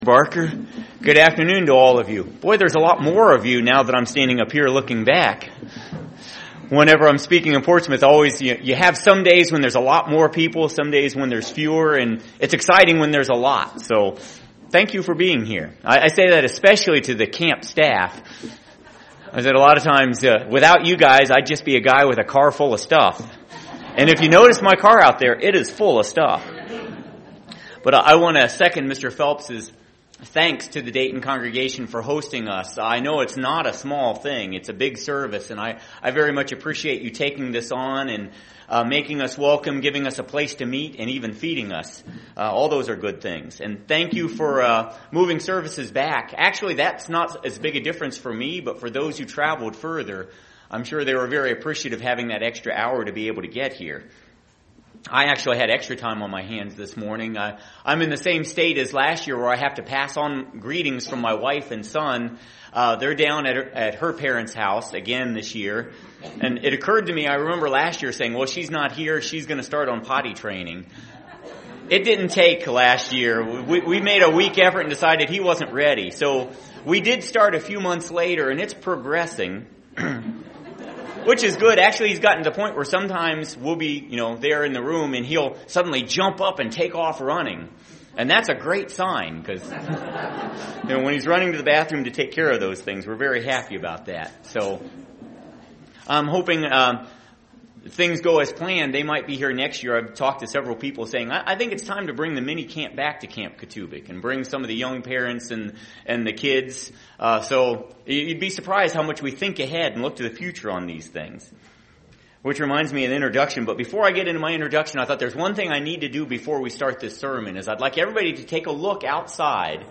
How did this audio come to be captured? This message was given on the Sabbath prior to Camp Cotubic.